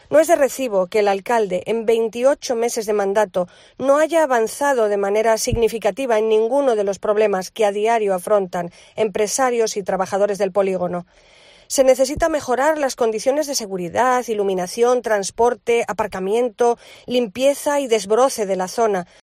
Sonsoles Sánchez-Reyes, portavoz PP. Polígono de Vicolozano